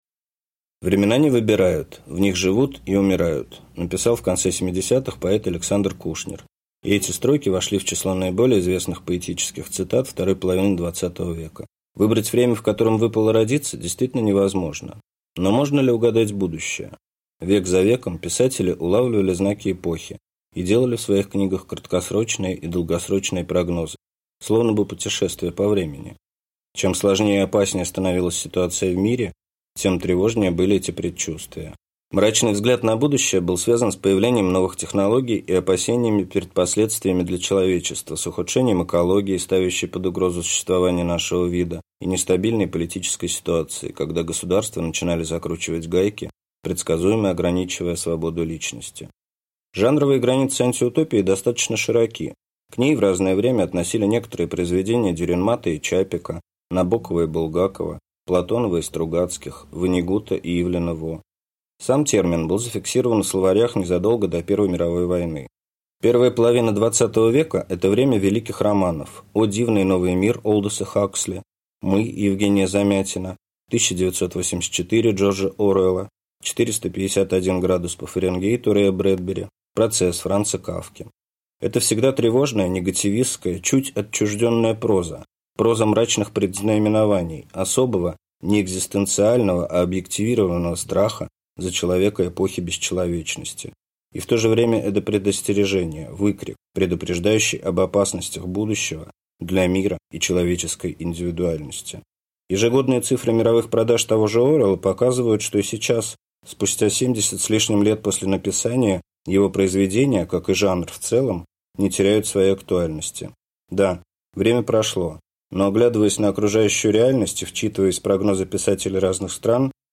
Аудиокнига Время вышло: Современная русская антиутопия | Библиотека аудиокниг